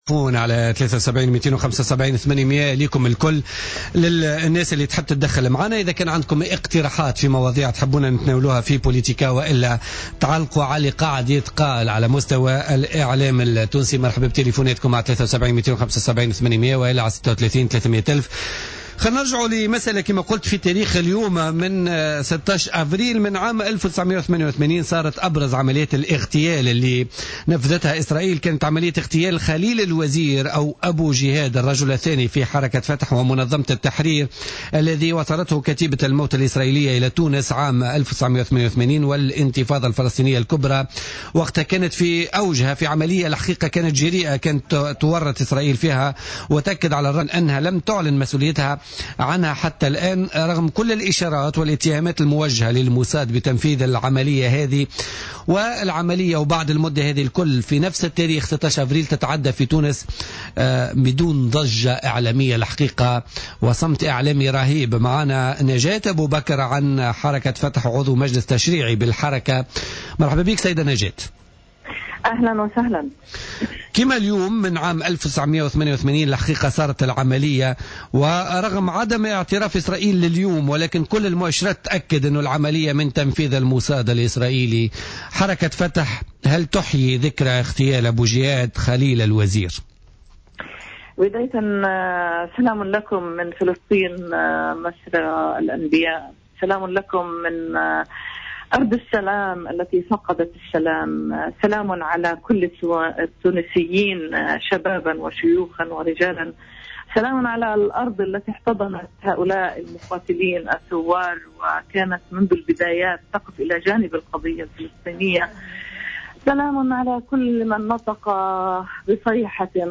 أكدت نجاة أبو بكر عضو مجلس تشريعي بحركة فتح خلال مداخلة لها اليوم في برنامج بوليتيكا على هامش احياء الذكرى 27 لإغتيال القيادي في حركة التحرير الفلسطينية خليل الوزير(ابو جهاد) في تونس أن الموساد الاسرائيلي كان يسعى إلى اجتثاث الثورة الفلسطينية التي تم ترحيلها من لبنان إلى تونس مشيرة إلى أن هذا الإغتيال أصاب العمود الفقري للقضية الفلسطينية وللثورة ولحركة فتح على حد قولها.